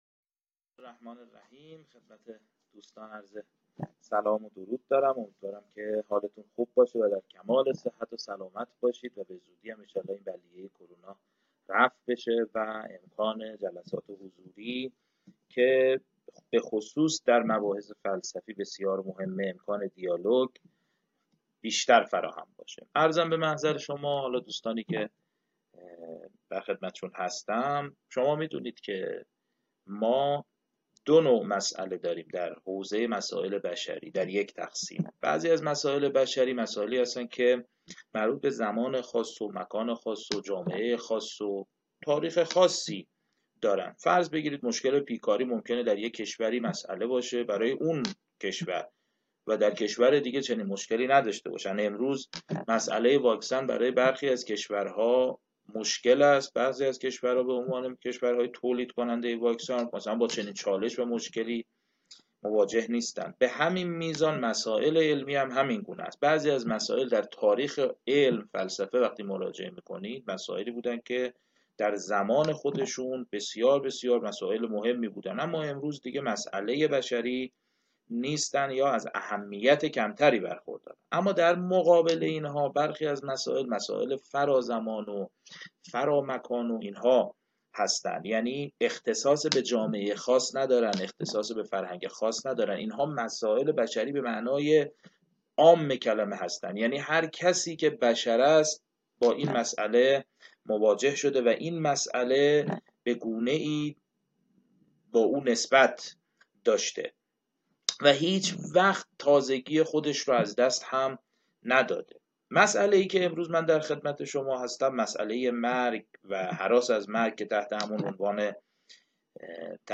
این درس‌گفتار به صورت مجازی از اینستاگرام شهر کتاب پخش شد.